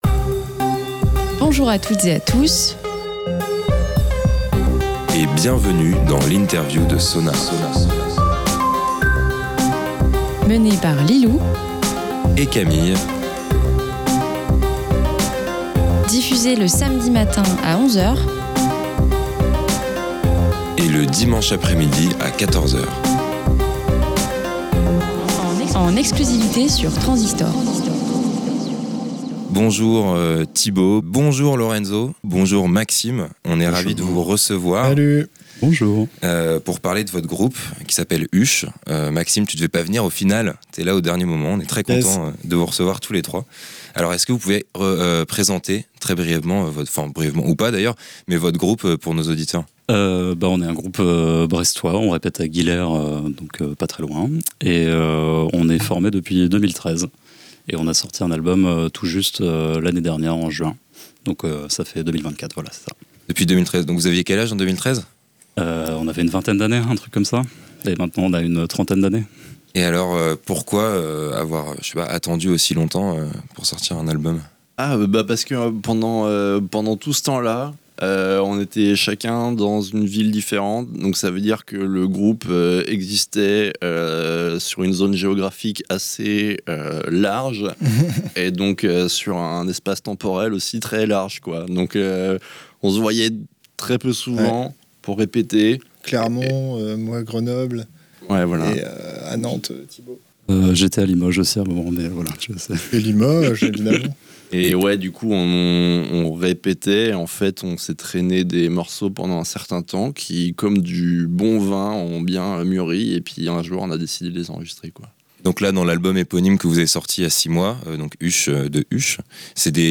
SONAR x INTERVIEW - HUCHE
Dans cet entretien, vous (re)découvrirez un trio de jeunes brestois à l'identité sonore bien marquée. On discute de leur patte, leurs inspirations, comment ils ont enregistré leur album... enfin en tout cas on parle musique !